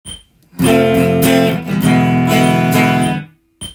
コードストロークで
「タタタ、タタタ、タタ」
（２つ目は倍の速さですが、、）